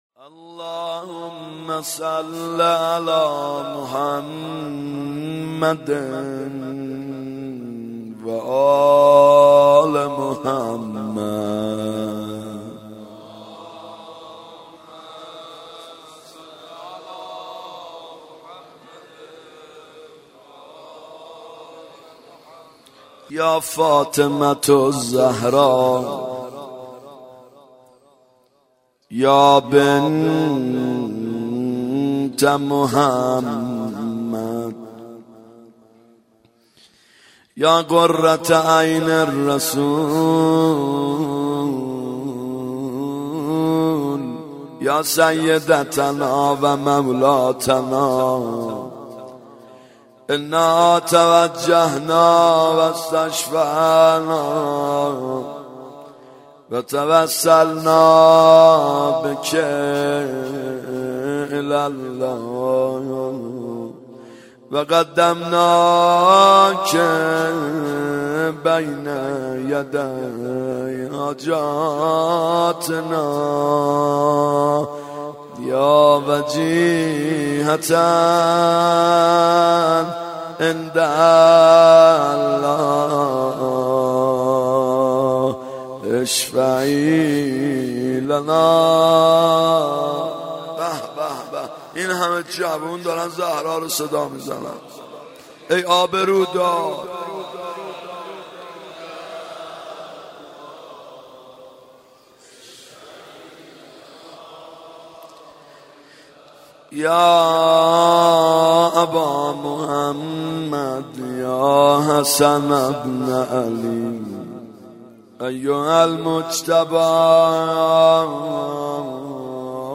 محرم 92 ( هیأت یامهدی عج)